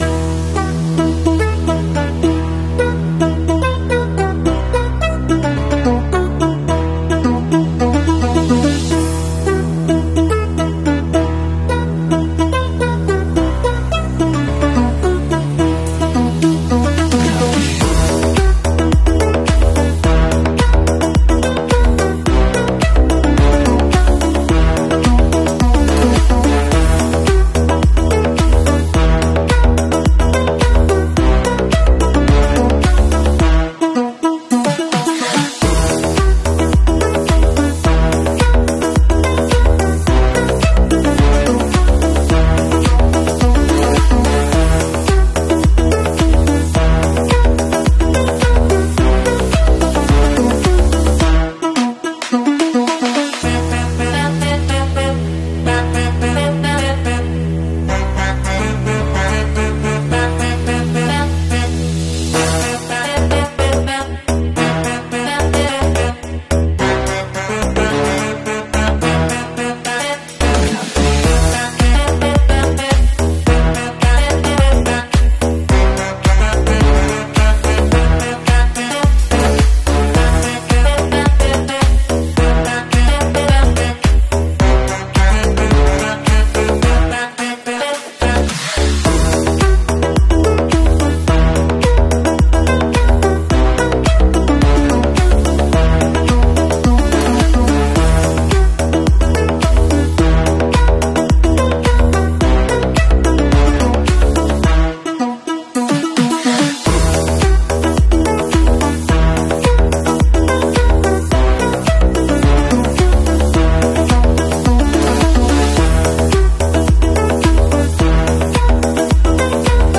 Bell 206 RC Helicopter Get sound effects free download